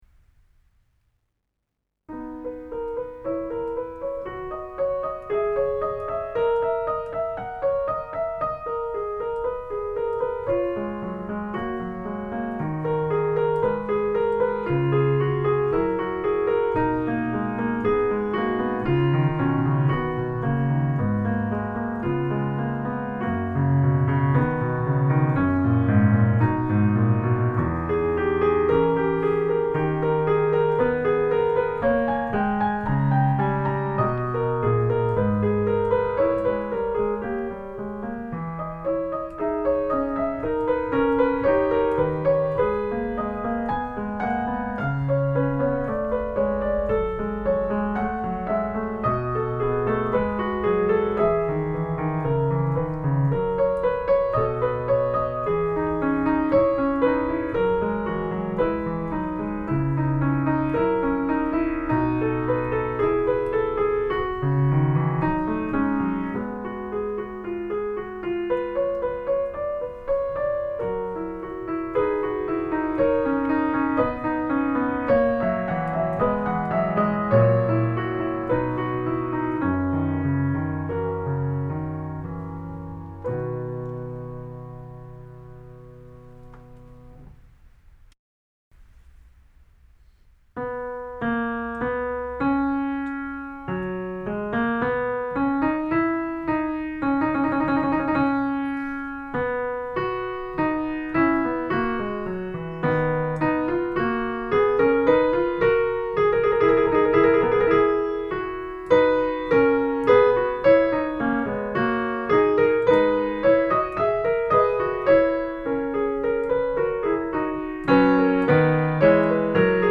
Bande demo Piano